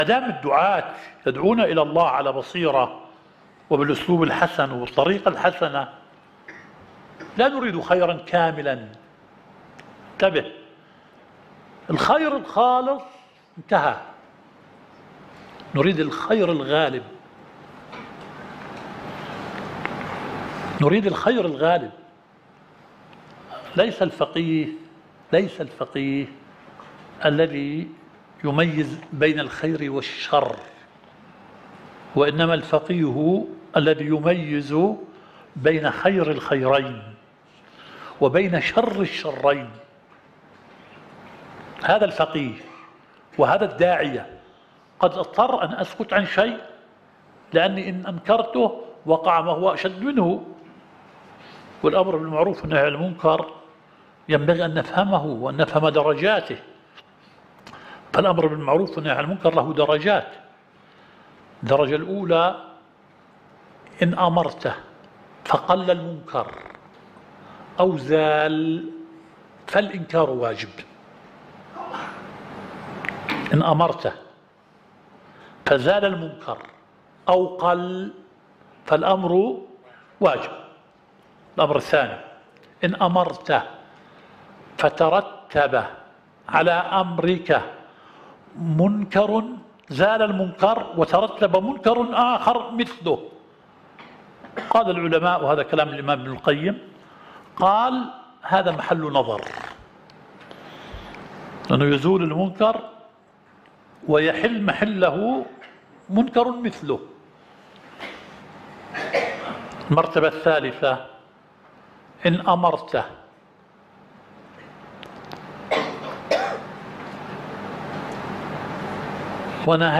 الدورة الشرعية الثالثة للدعاة في اندونيسيا – منهج السلف في التعامل مع الفتن – المحاضرة الثالثة.